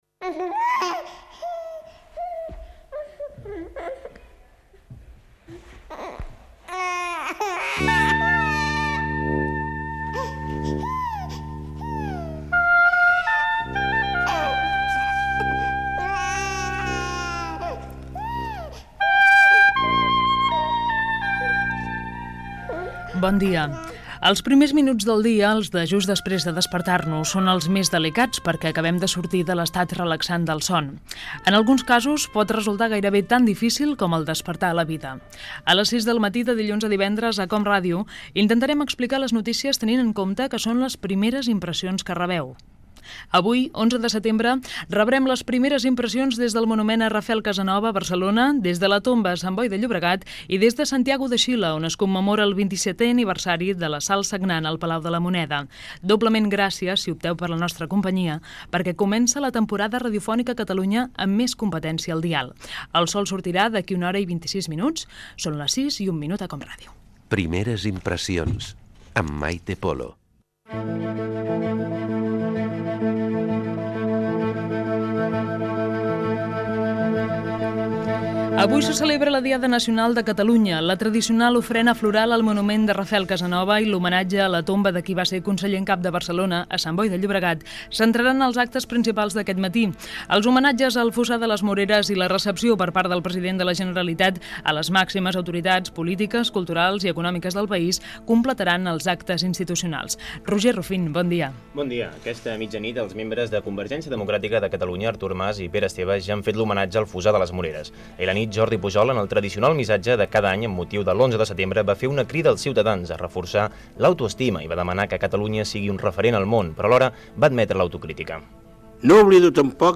Careta del programa, presentació de la primera edició, sumari, hora, indicatiu, la Diada Nacional de Catalunya (fragments del dicurs del president de la Generalitat Jordi Pujol), atemptat d'ETA a Euskadi
Informatiu